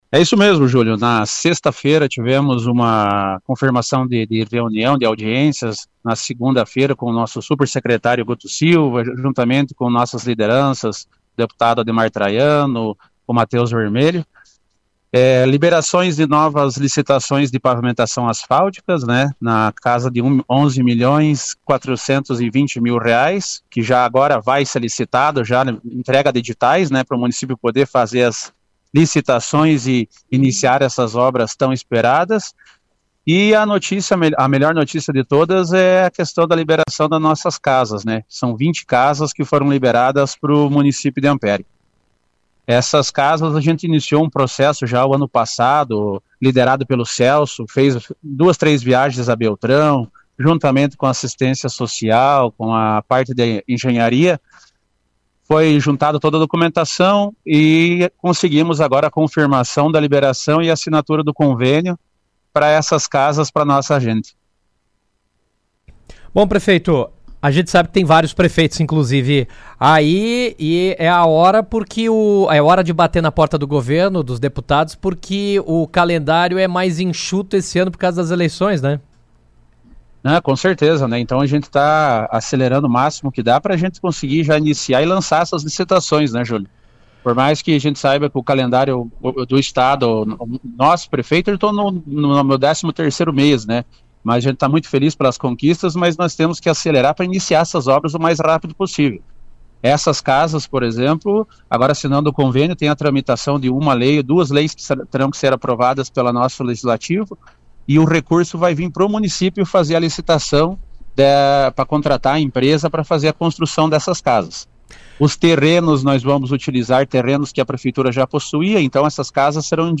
O prefeito de Ampére, Douglas Potrich, participou por telefone do Jornal RA 2ª Edição desta terça-feira, 27, e falou sobre uma série de investimentos e convênios firmados durante recente viagem a Curitiba, com foco no fortalecimento do desenvolvimento do município. Entre os principais anúncios, o gestor destacou a liberação de mais de R$ 11 milhões destinados a obras de pavimentação urbana, além da confirmação da construção de 20 novas unidades habitacionais em terrenos pertencentes ao município, ampliando o acesso à moradia para a população.